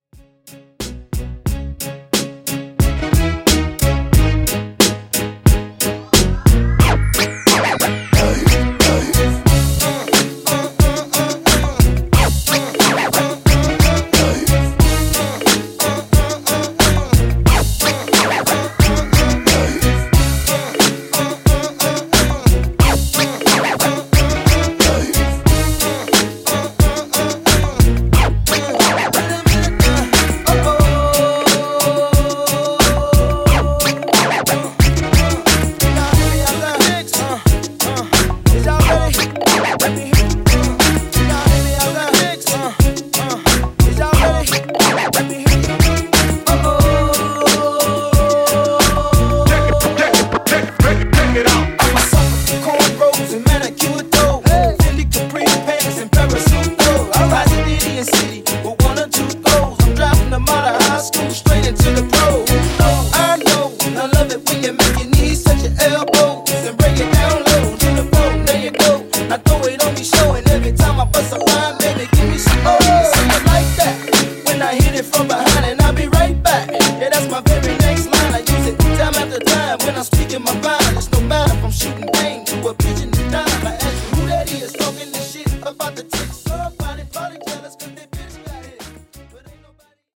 Mashup Edit)Date Added